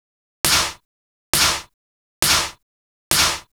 Session 04 - Snare.wav